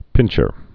(pĭnshər)